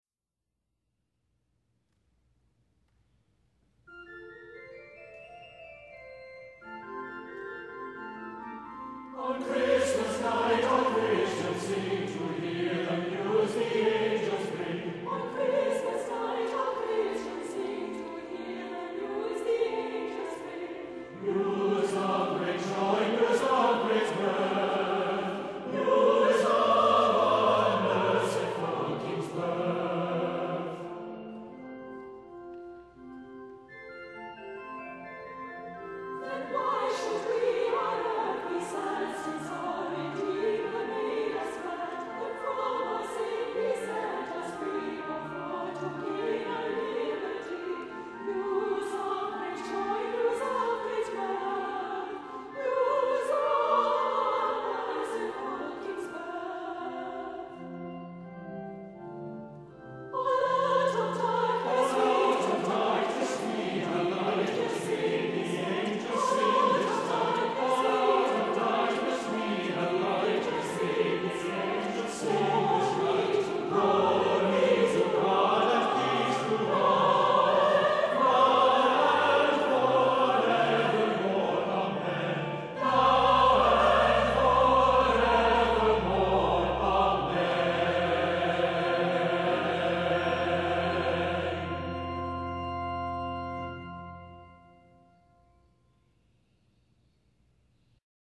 An exuberant setting